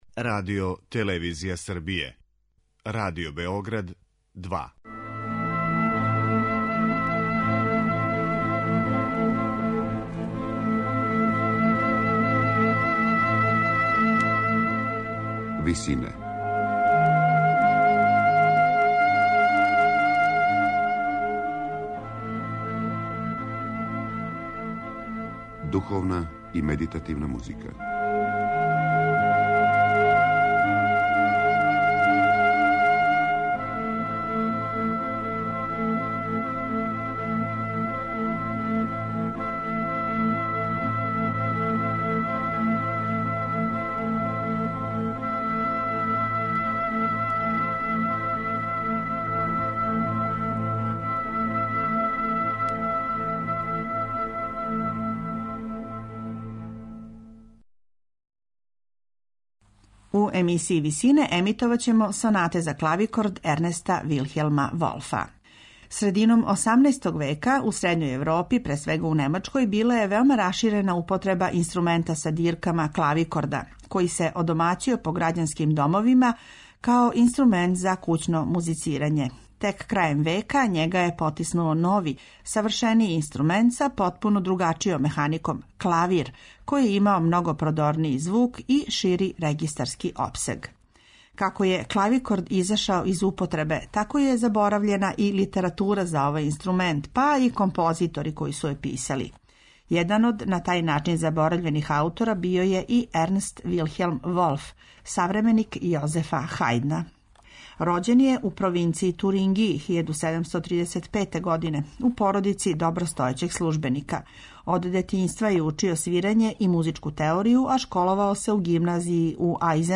Слушаћете сонате за клавикорд, мало познатог ранокласичарског композитора Ернста Вилхелма Волфа.
На инструменту епохе